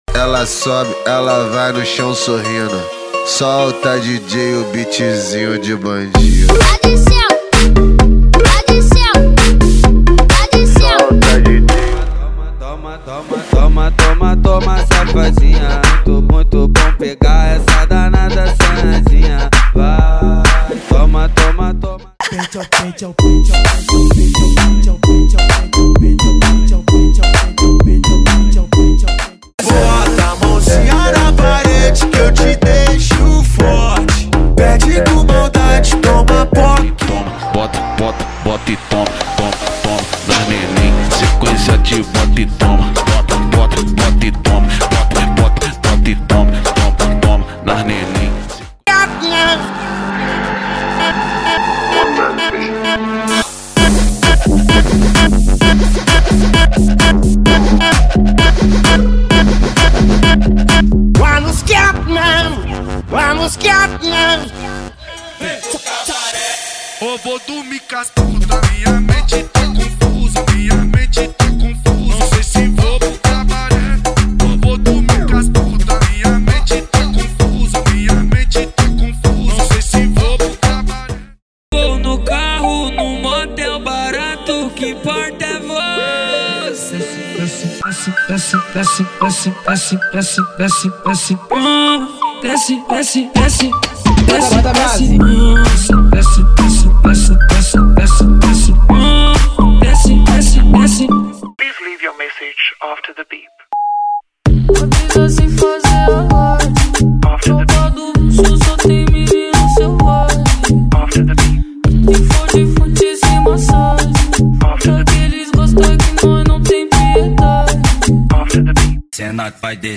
• Eletro Funk = 50 Músicas
• Sem Vinhetas
• Em Alta Qualidade